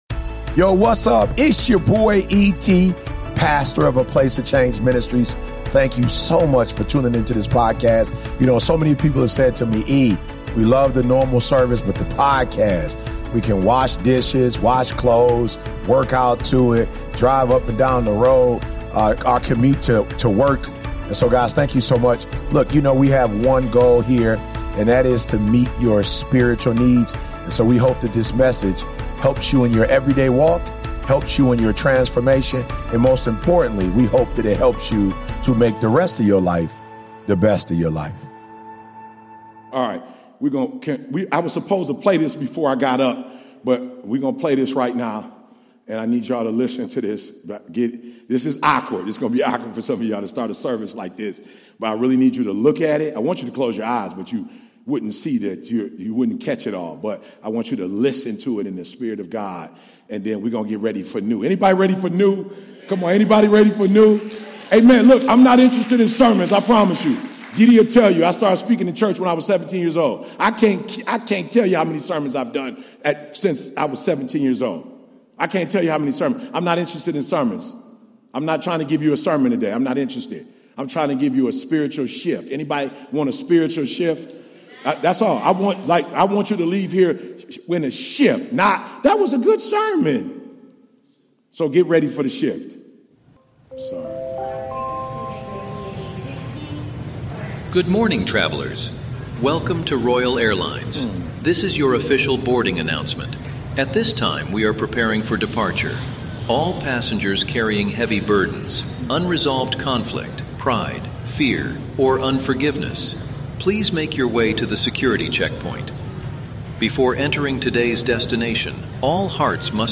APOC Ministry You’re Not Behind, You’re Right On Time - Identity Comes Before Destiny 2026-02-14 Likes 1 Download Share 🔥 Feel like you're behind in life?